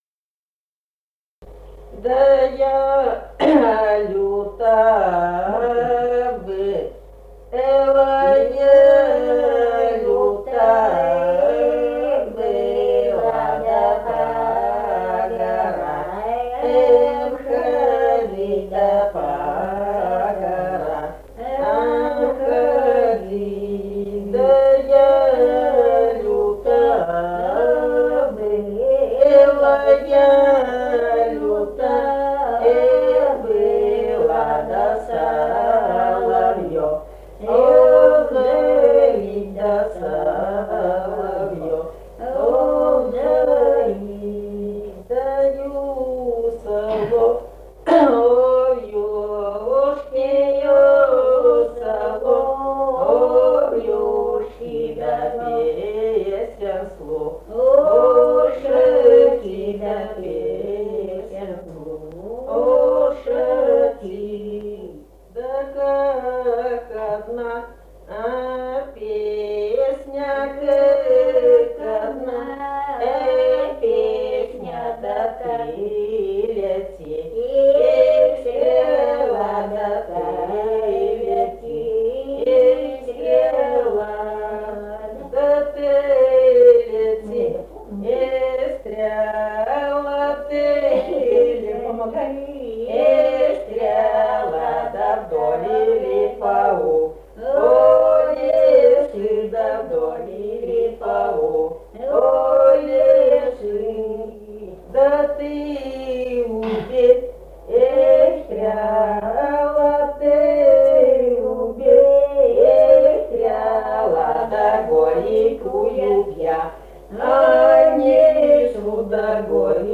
Республика Казахстан, Восточно-Казахстанская обл., Катон-Карагайский р-н, с. Белое, июль 1978.